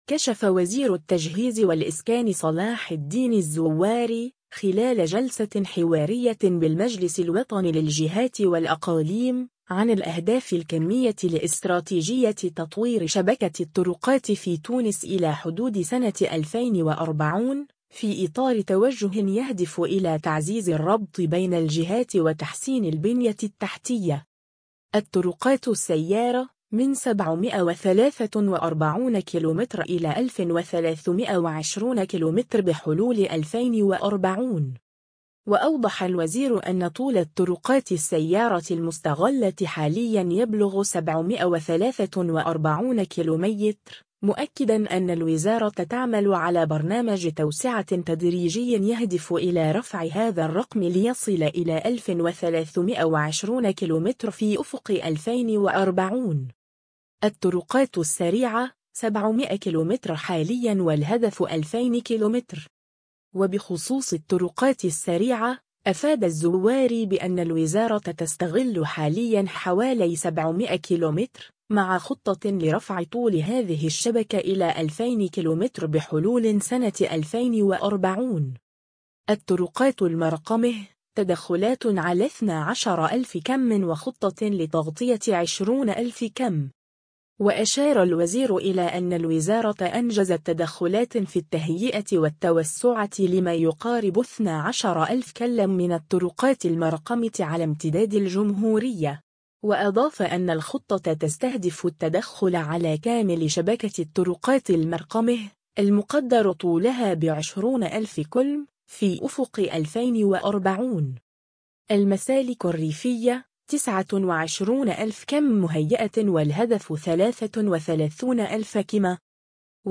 كشف وزير التجهيز والإسكان صلاح الدين الزواري، خلال جلسة حوارية بالمجلس الوطني للجهات والأقاليم، عن الأهداف الكمية لاستراتيجية تطوير شبكة الطرقات في تونس إلى حدود سنة 2040، في إطار توجه يهدف إلى تعزيز الربط بين الجهات وتحسين البنية التحتية.